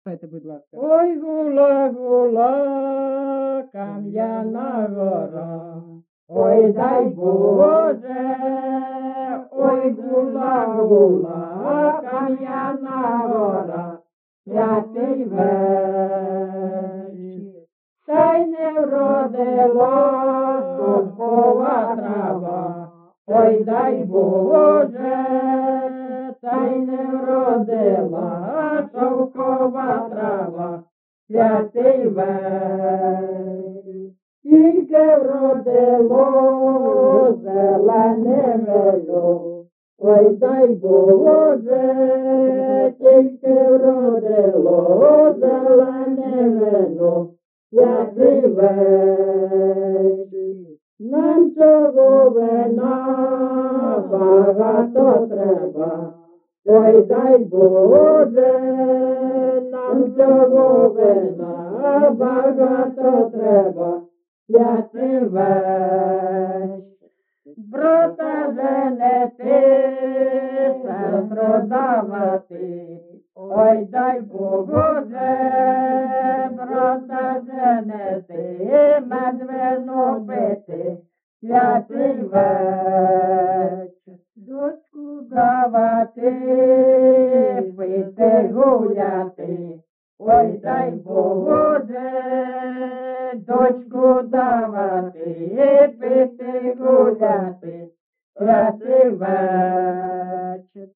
ЖанрКолядки
Місце записус. Лиман, Зміївський (Чугуївський) район, Харківська обл., Україна, Слобожанщина